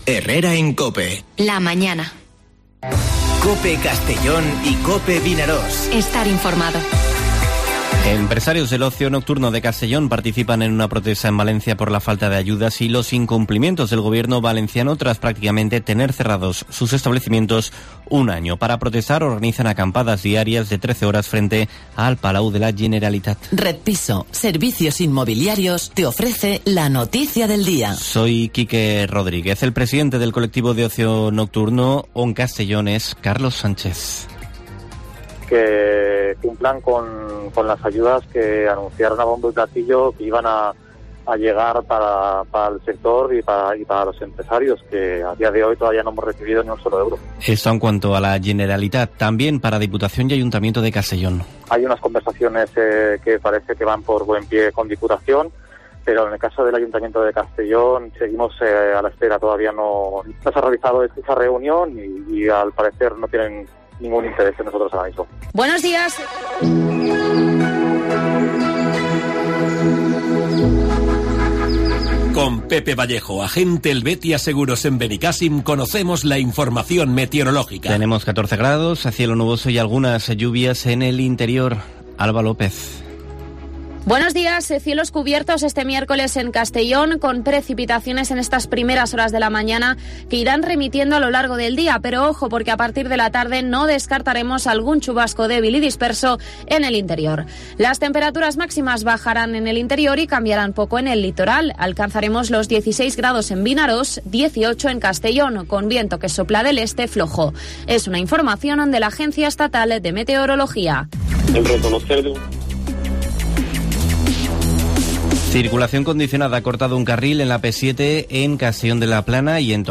Informativo Herrera en COPE en la provincia de Castellón (14/04/2021)